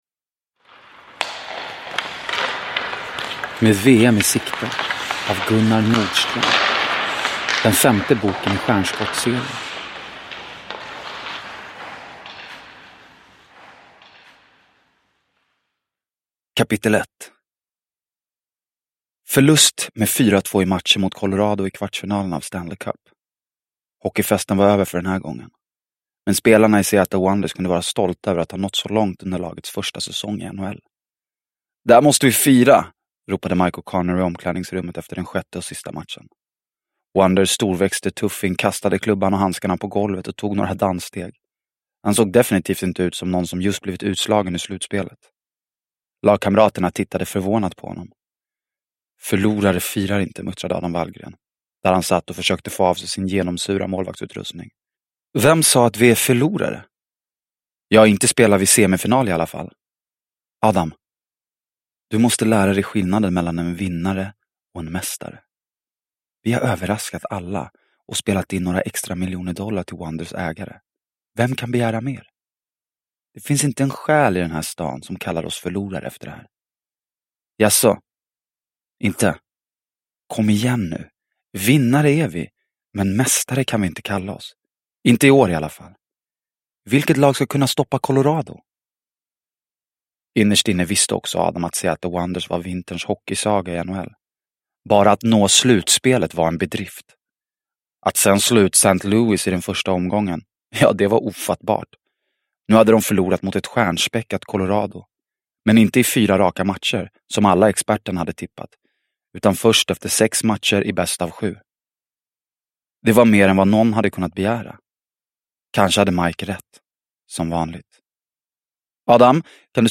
Med VM i sikte – Ljudbok – Laddas ner